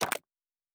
UI Tight 08.wav